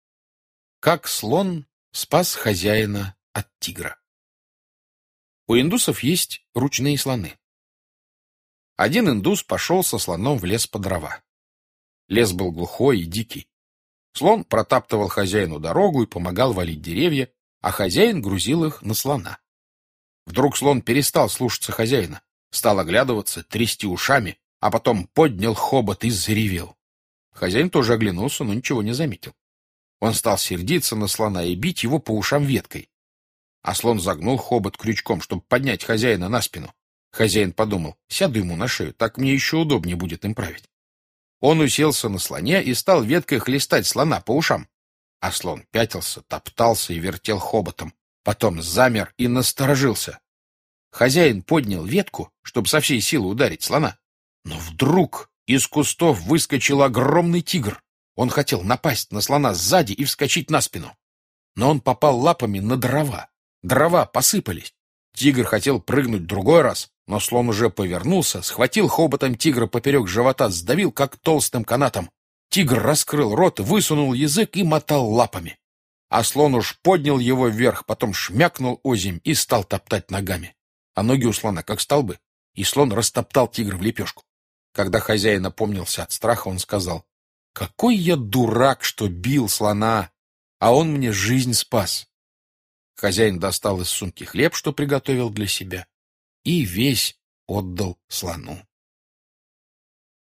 Слушать Как слон спас хозяина от тигра - аудио рассказ Житкова Б.С. В джунглях хозяин работал со слоном. Вдруг тот перестал выполнять команды.